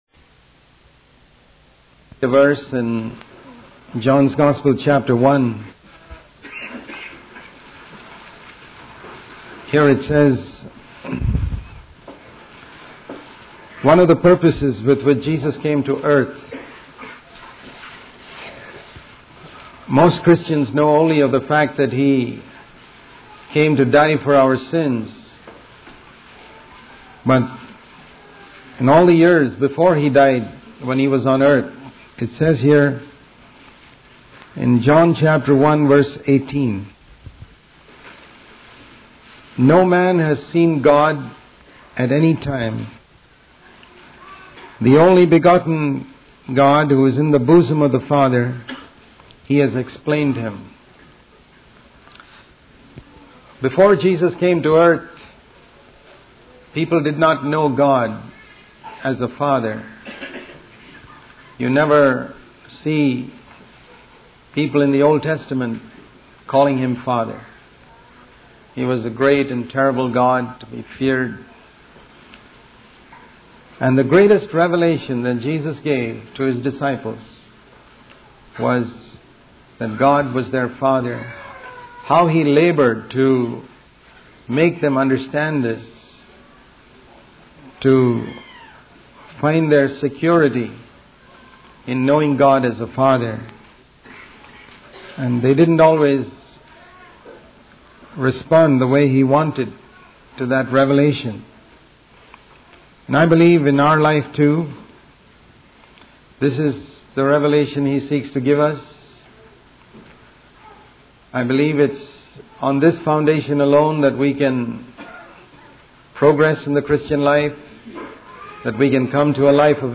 In this sermon, the preacher focuses on the importance of speech and the power of words. He starts by emphasizing the command in Ephesians 4:28 to stop stealing completely, highlighting the need for a clear conscience.